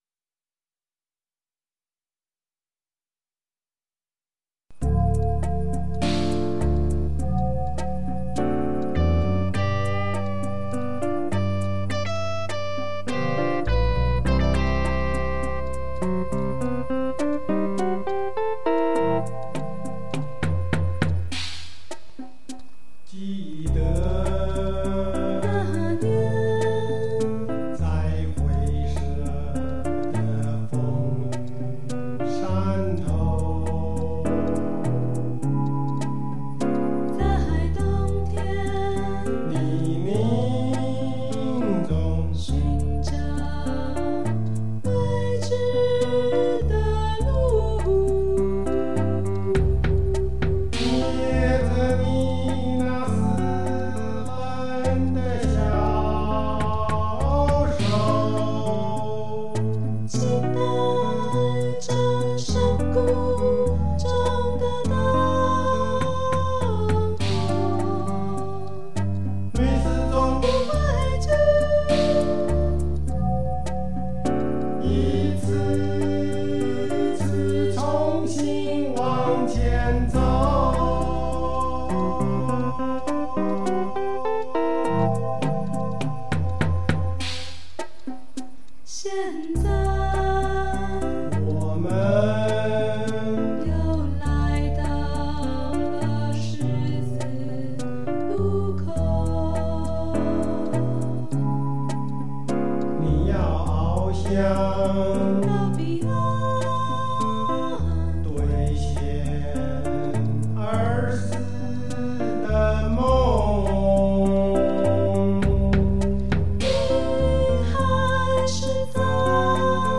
演唱曲 Singing- 請先關掉頁首收音機，再按此圖示- Please tern off the radio on the top of this page, then click here.